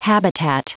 Prononciation du mot habitat en anglais (fichier audio)
Prononciation du mot : habitat